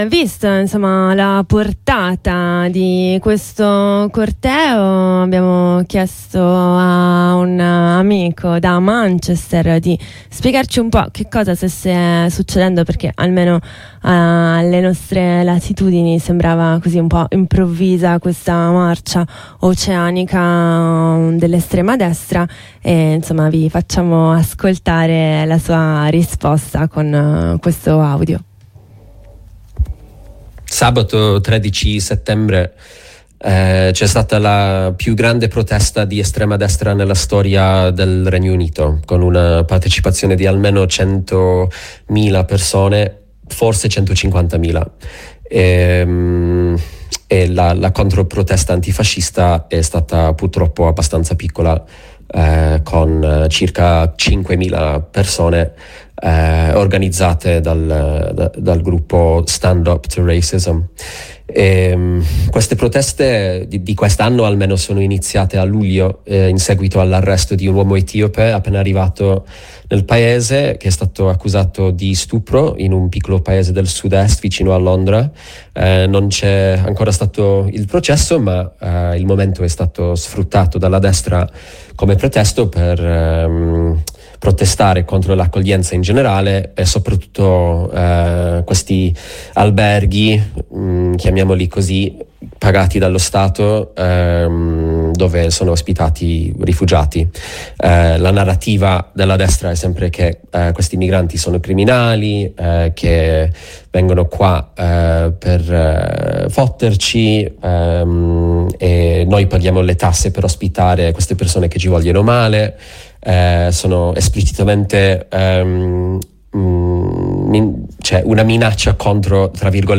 Ascolta o scarica l’approfondimento: